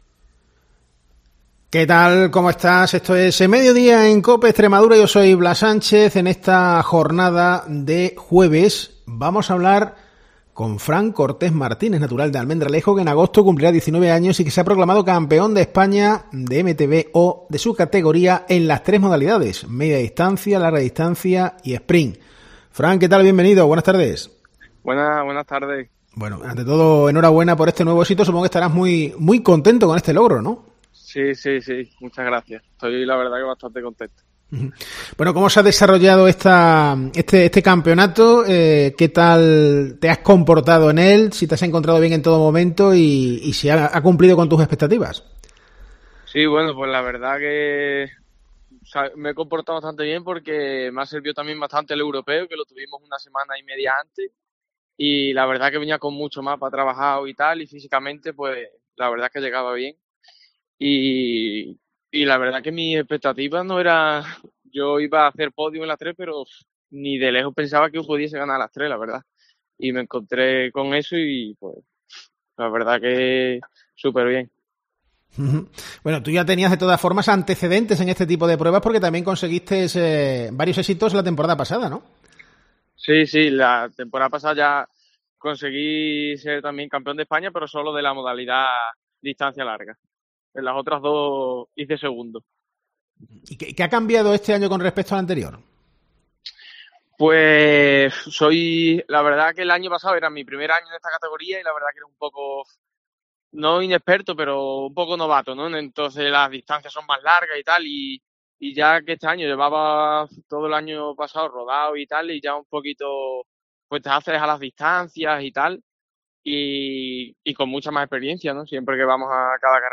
En COPE hemos hablado con él.